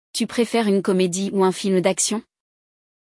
Durante o episódio, você também ouvirá nativos conversando, o que é uma excelente forma de treinar sua compreensão oral e se acostumar ao ritmo do francês falado no cotidiano.